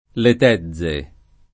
le t$zze o